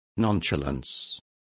Complete with pronunciation of the translation of nonchalance.